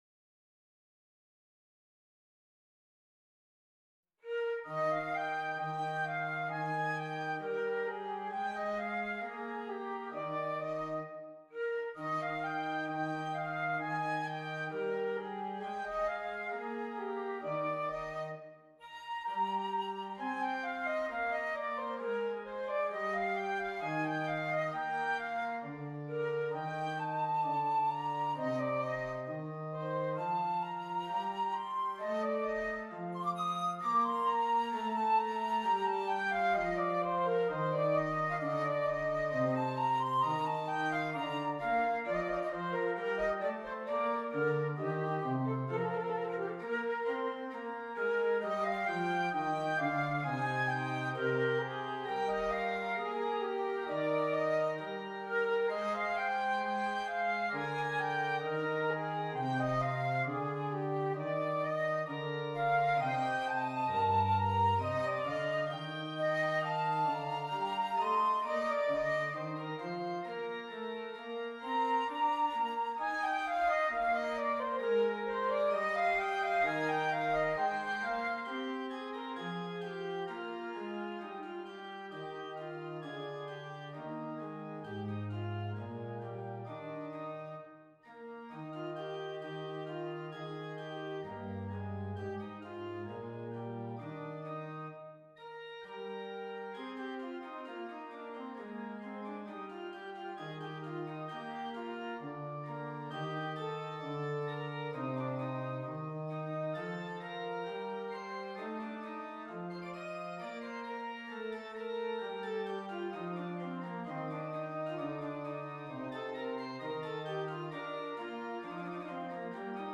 Flute and Keyboard
solo flute and keyboard (piano or organ)